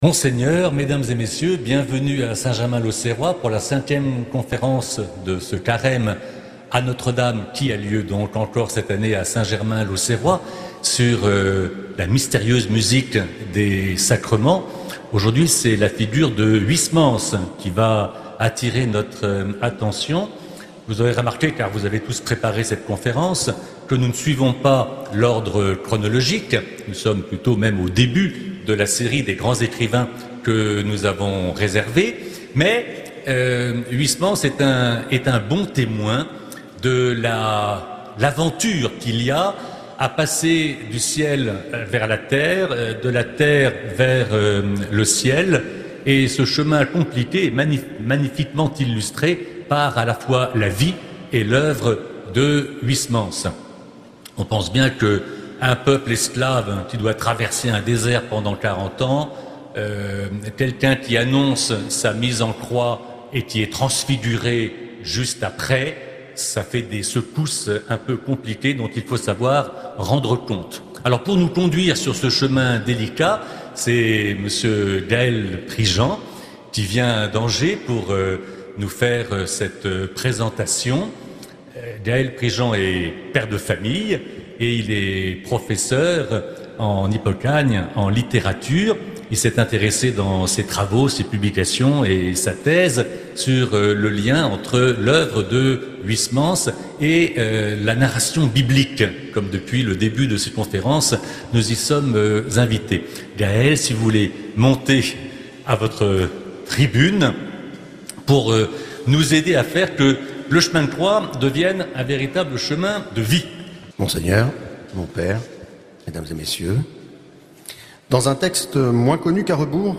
Conférences de carême